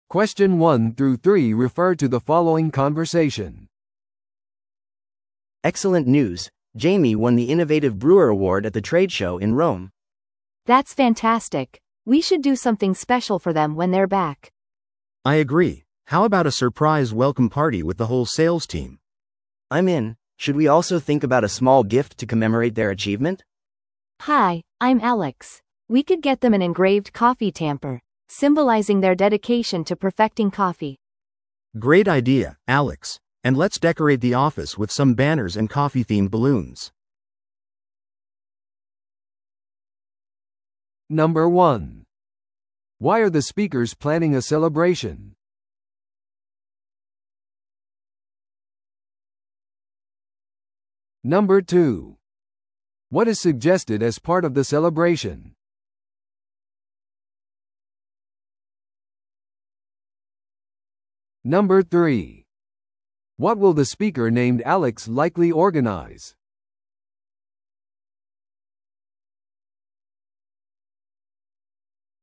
TOEICⓇ対策 Part 3｜受賞した醸造者へのサプライズパーティー企画について – 音声付き No.270